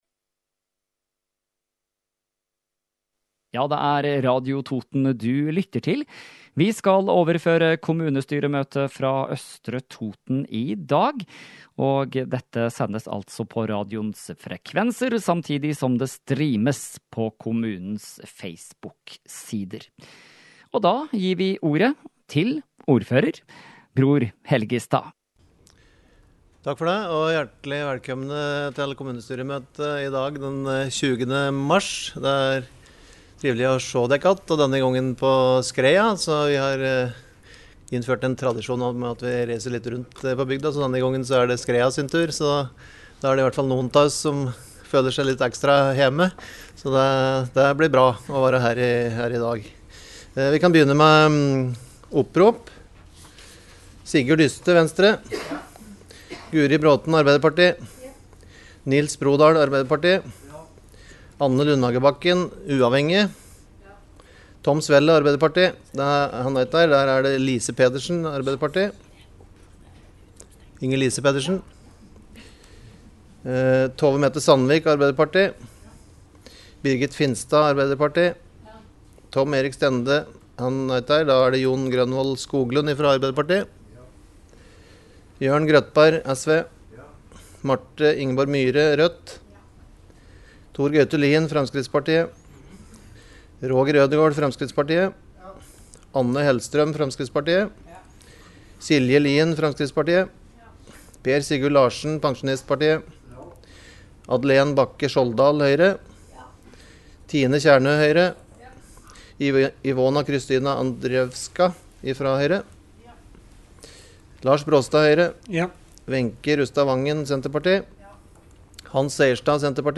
Kommunestyre fra Østre Toten 20. mars – Lydfiler lagt ut | Radio Toten
Møte ble hold på Kulturhuset i Skreia